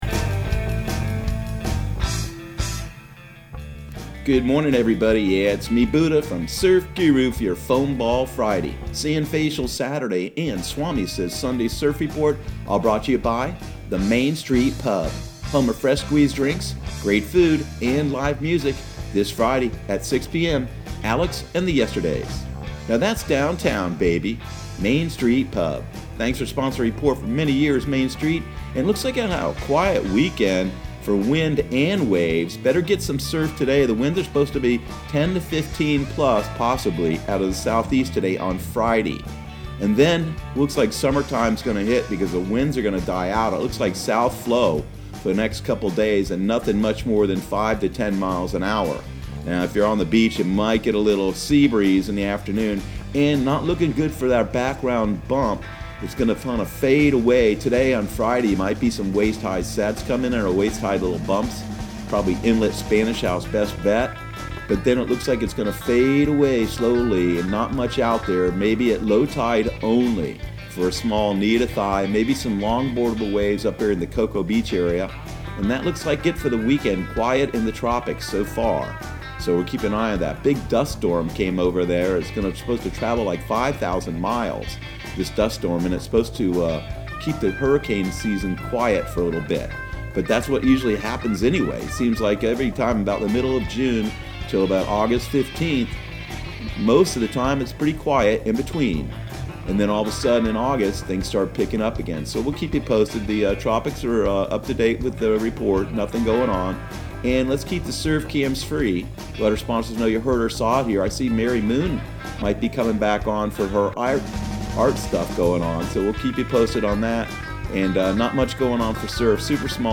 Surf Guru Surf Report and Forecast 06/19/2020 Audio surf report and surf forecast on June 19 for Central Florida and the Southeast.